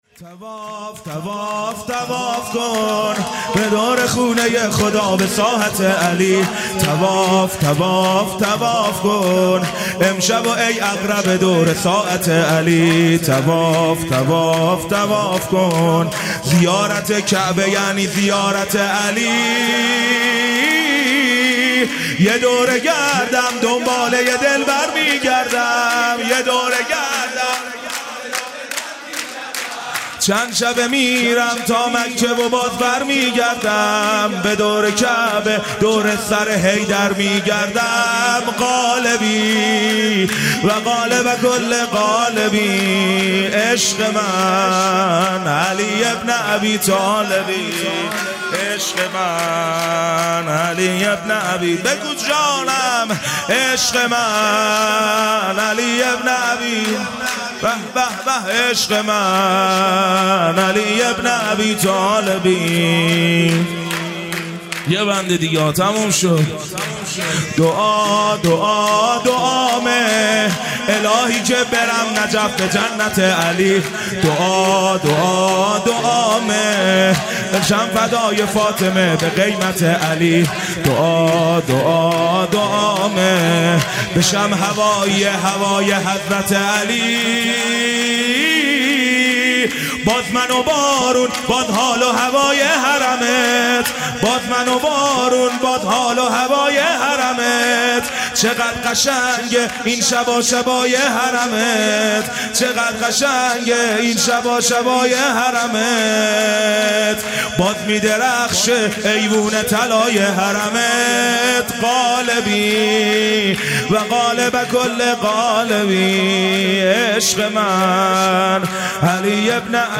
هیئت دانشجویی فاطمیون دانشگاه یزد
سرود